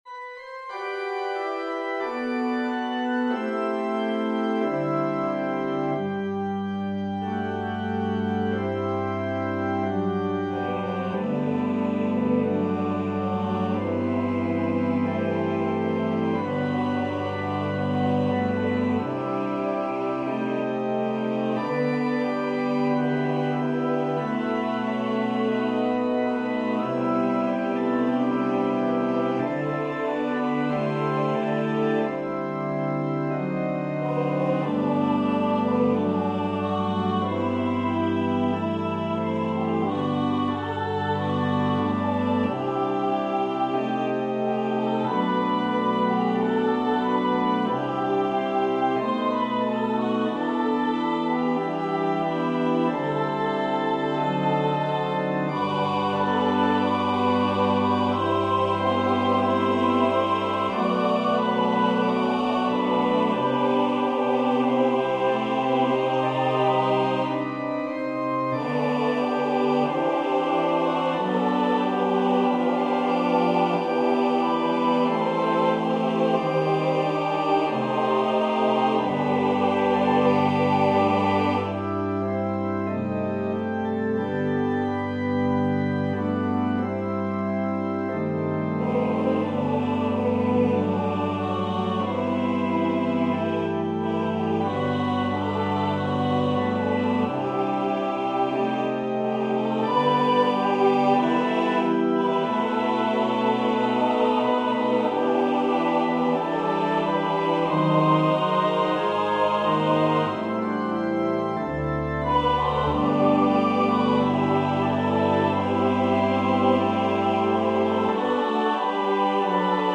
Voicing/Instrumentation: SATB , Organ/Organ Accompaniment